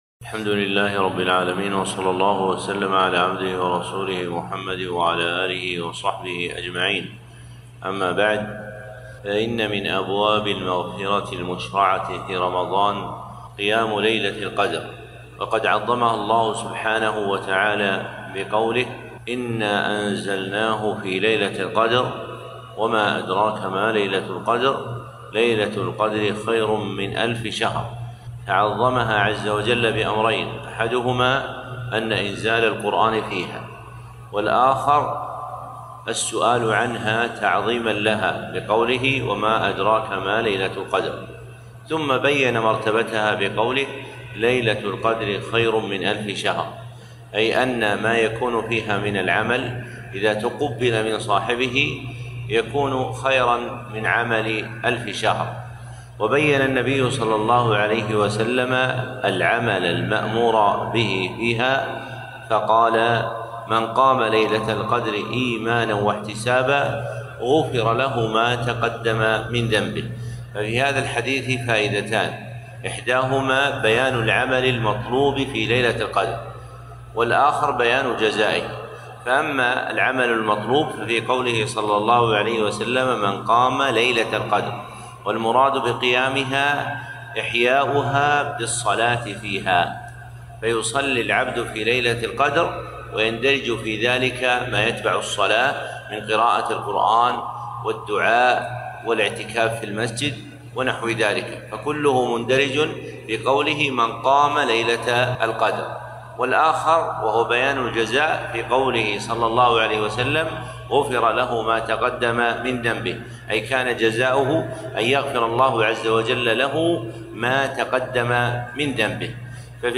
كلمة - في رحاب ليلة القدر